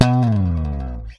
SLIDEPICK 1.wav